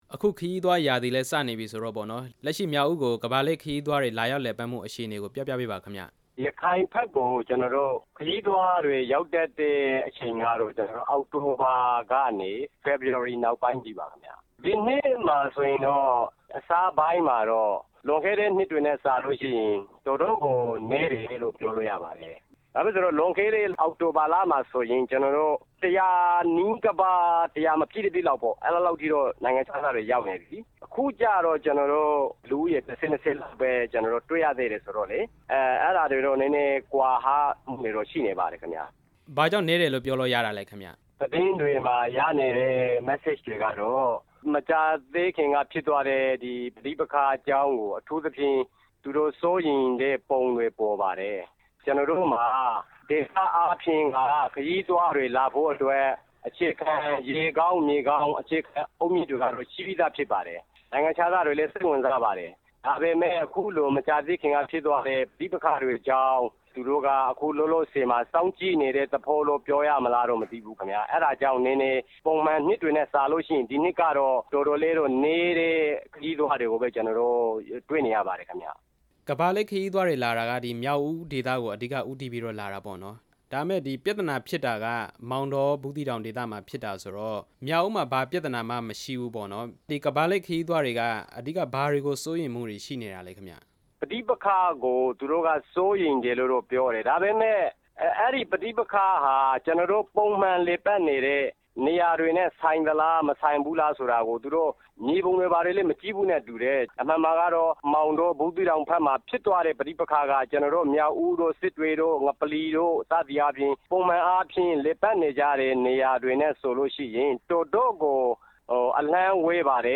ရခိုင်ပြည်နယ်ကို ကမ္ဘာလှည့်ခရီးသည်လည်ပတ်မှုအကြောင်း မေးမြန်းချက်